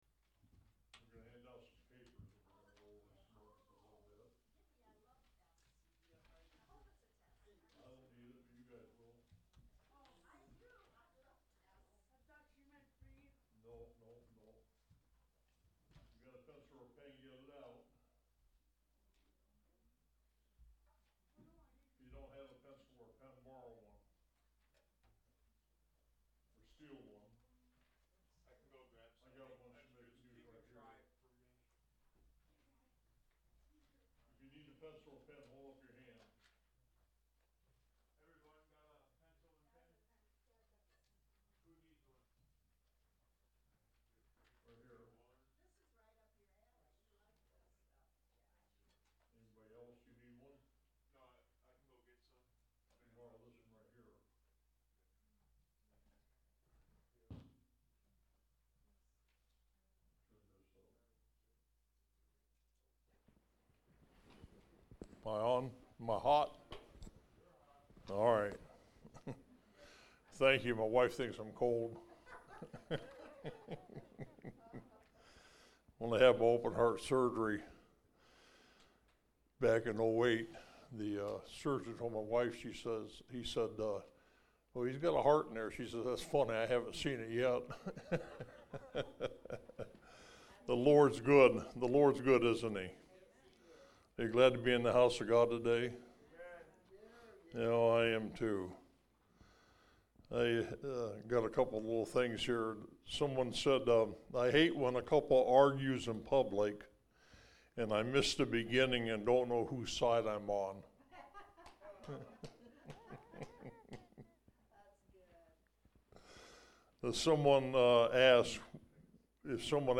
Online Sermons – Walker Baptist Church
From Series: "Sunday School"